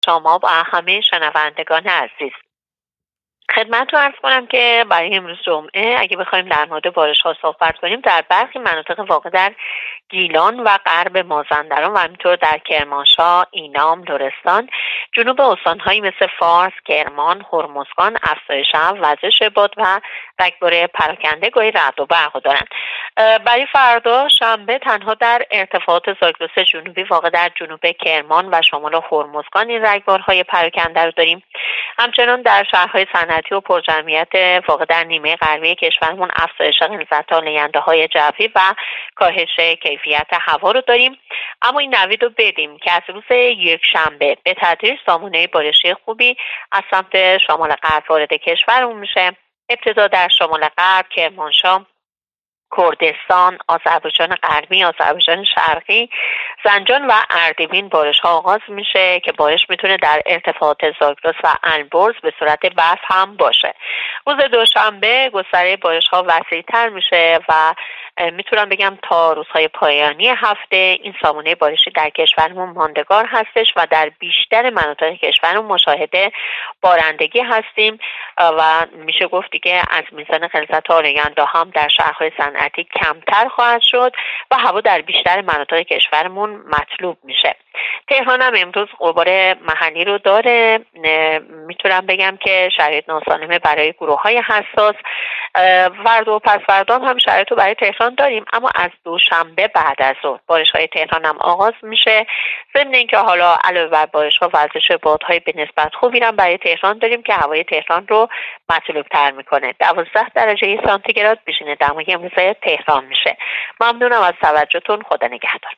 گزارش رادیو اینترنتی پایگاه‌ خبری از آخرین وضعیت آب‌وهوای ۱۴ آذر؛